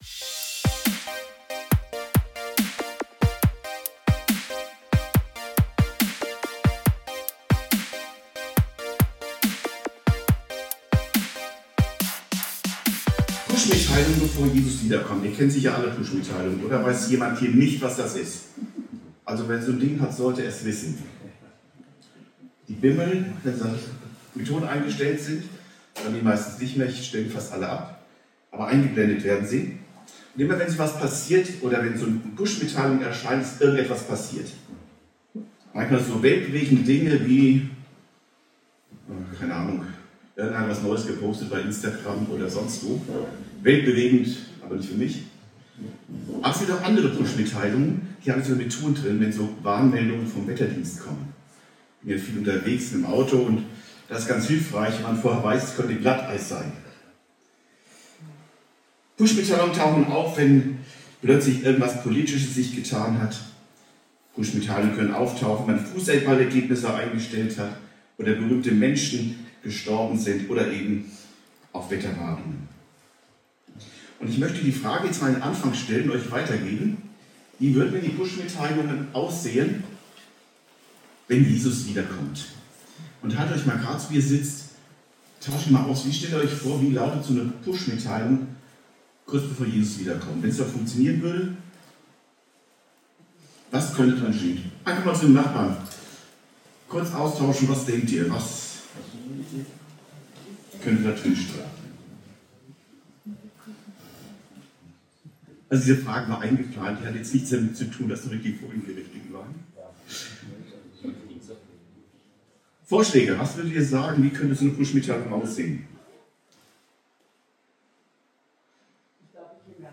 Pushmitteilungen bevor Jesus wiederkommt ~ Predigten u. Andachten (Live und Studioaufnahmen ERF) Podcast